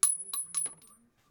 casing.wav